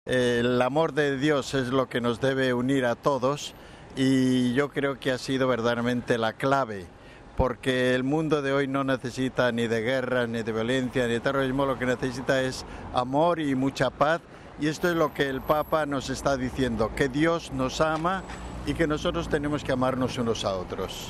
Hemos recogido, también, el testimonio de monseñor Félix Lázaro Martínez, obispo de la diócesis puertorriqueña de Ponce.